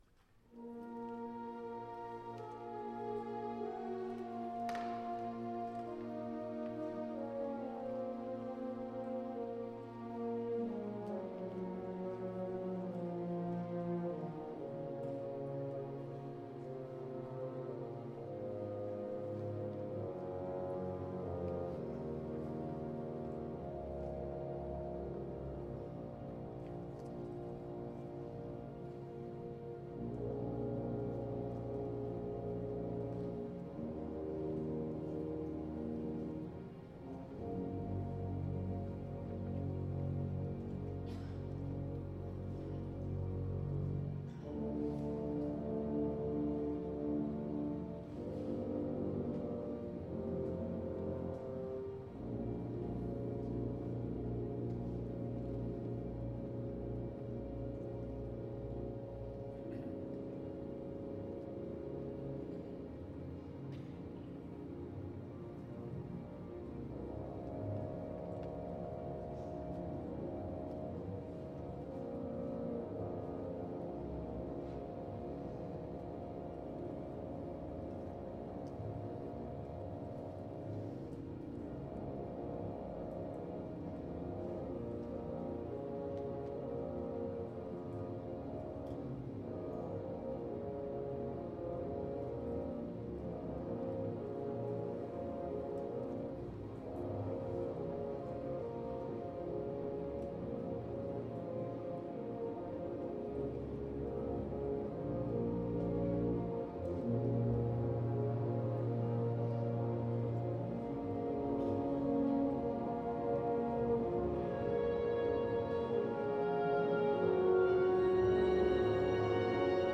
Orchestra version
Style: Classical